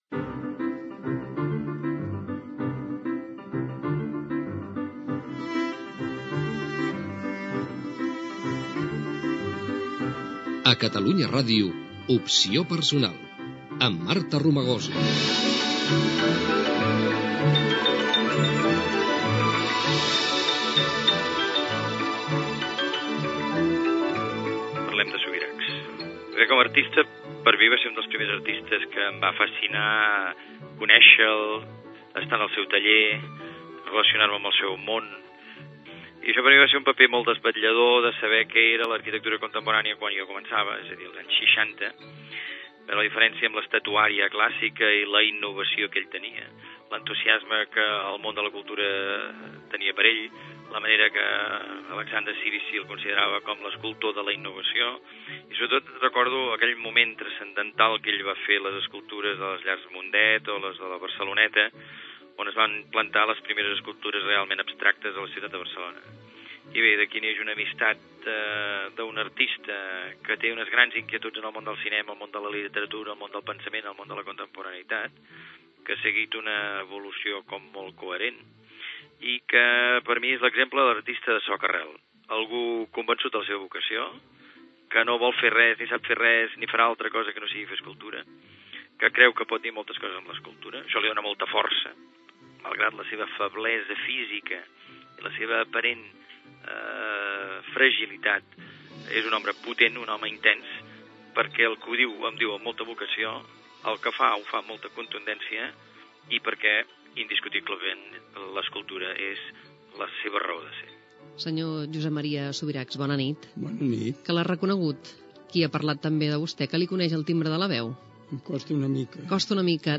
Careta del programa
entrevista a l'escultor Josep Maria Subirachs: l'any Gaudí i la seva obra a la Sagrada Família de Barcelona